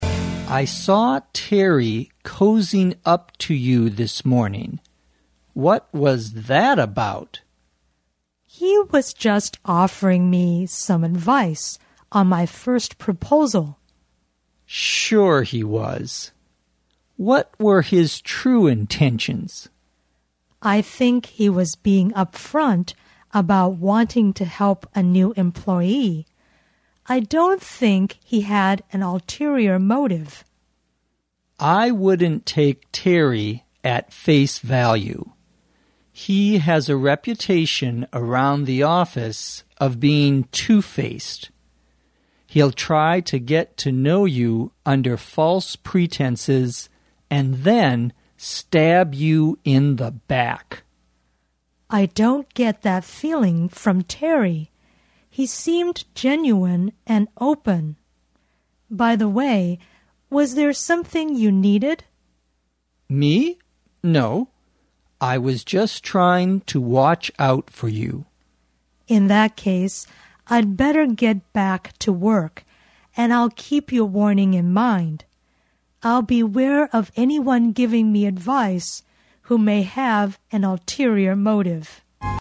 地道美语听力练习:两面派